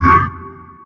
4aef571f59 Divergent / mods / Soundscape Overhaul / gamedata / sounds / monsters / poltergeist / hit_1.ogg 16 KiB (Stored with Git LFS) Raw History
hit_1.ogg